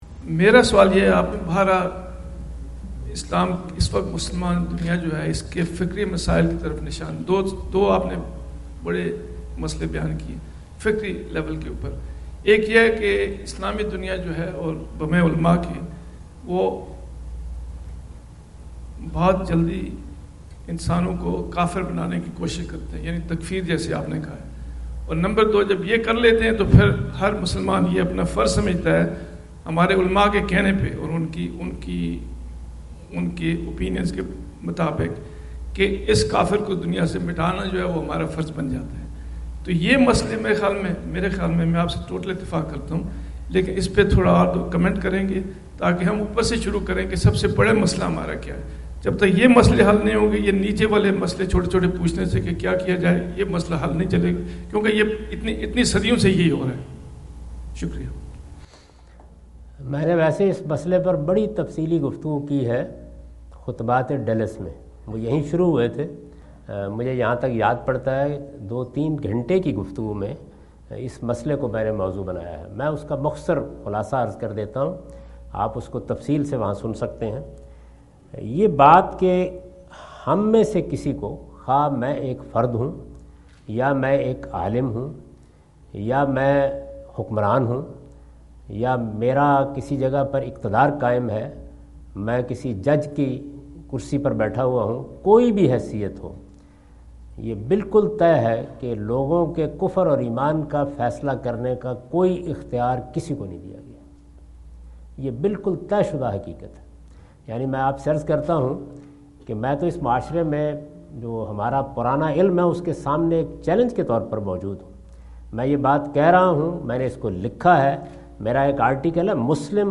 Javed Ahmad Ghamidi answer the question about "Intellectual issues facing Muslim Ummah" During his US visit in Dallas on October 08,2017.
جاوید احمد غامدی اپنے دورہ امریکہ2017 کے دوران ڈیلس میں "مسلم امہ کو درپیش دو فکری مسائل" سے متعلق ایک سوال کا جواب دے رہے ہیں۔